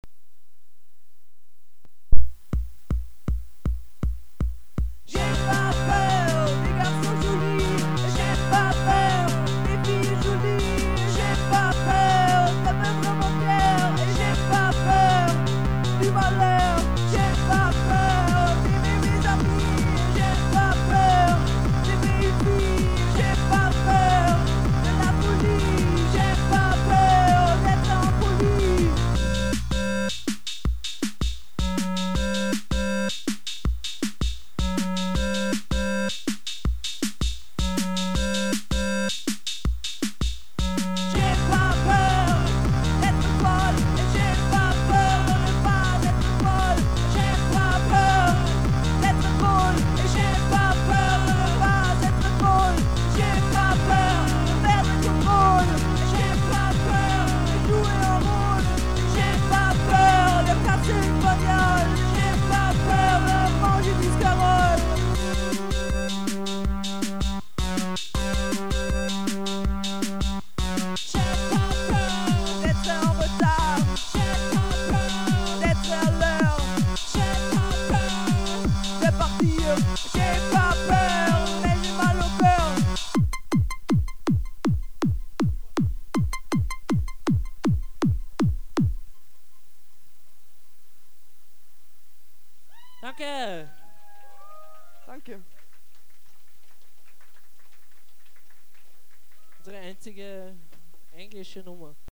Very bad live rec.